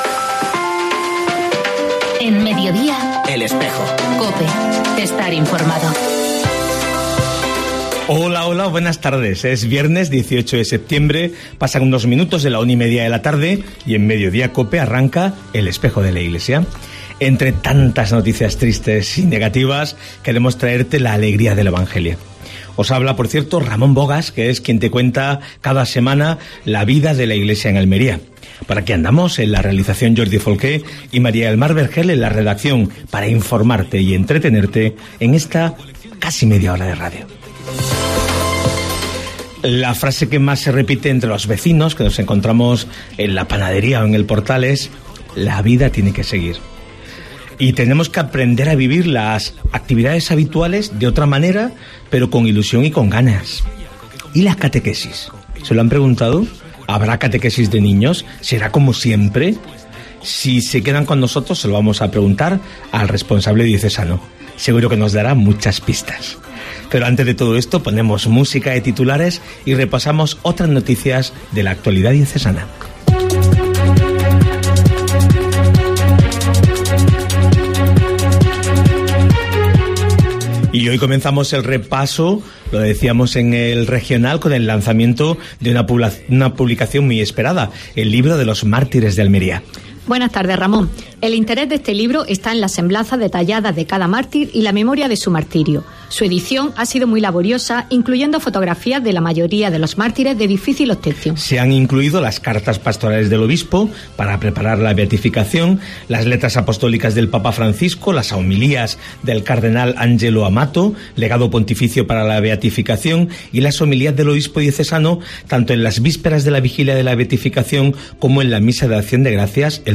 AUDIO: Actualidad de la Iglesia en Almería. Entrevista